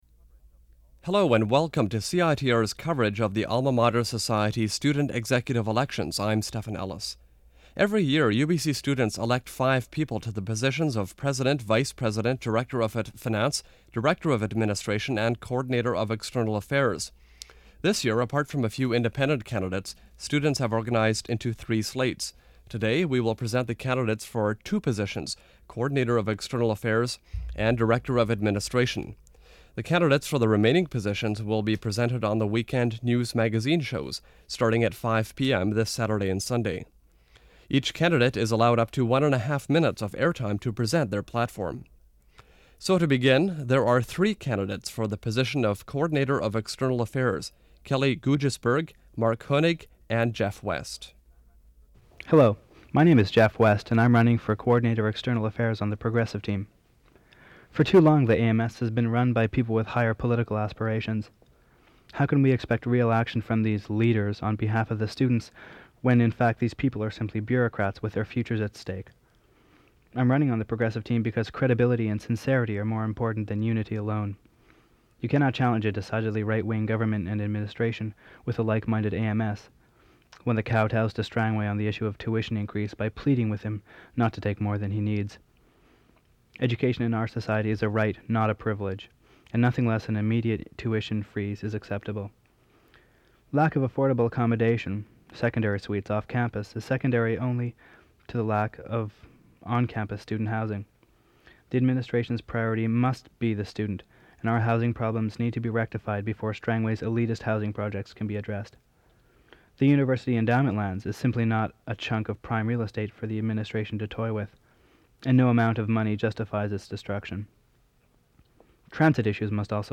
prepared statements by candidates for the University of British Columbia Alma Mater Society elections of 1991